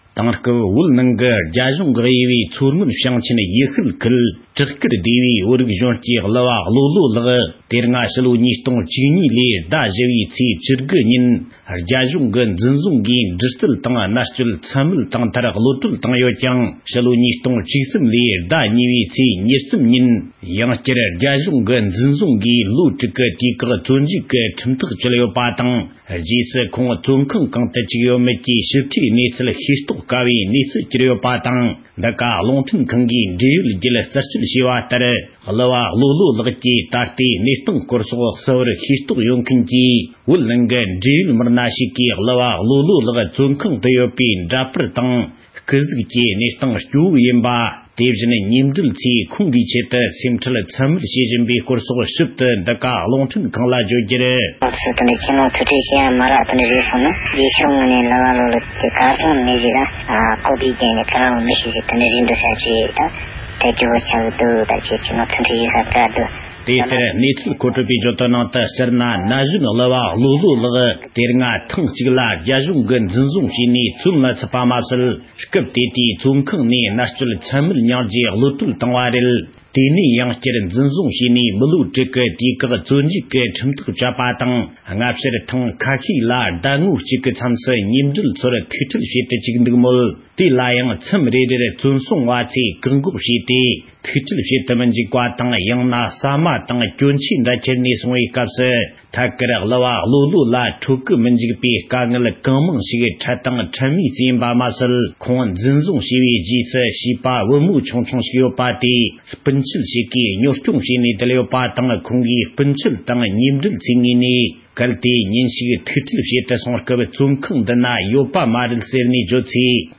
སྒྲ་ལྡན་གསར་འགྱུར།
གསར་འགོད་པ